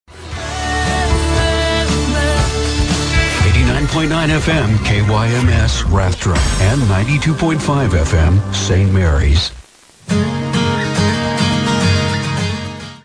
KYMS Top of the Hour Audio: